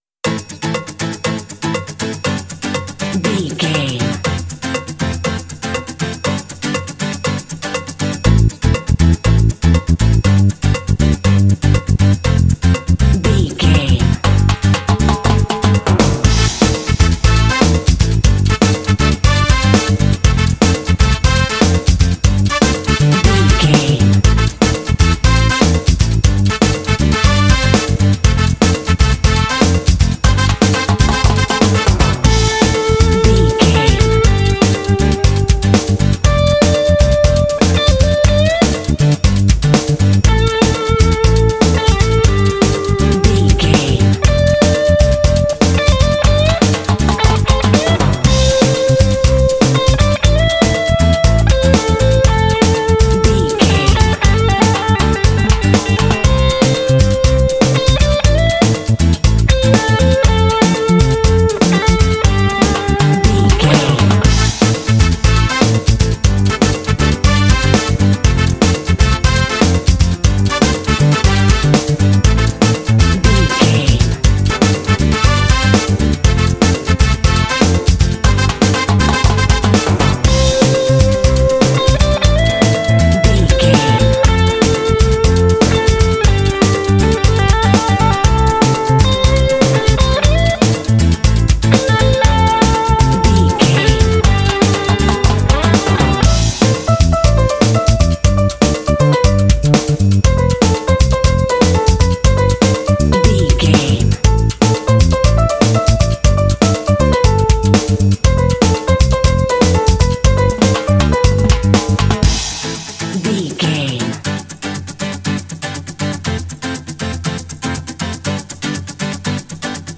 Uplifting
Aeolian/Minor
smooth
lively
energetic
driving
percussion
drums
acoustic guitar
bass guitar
electric guitar
piano
brass
latin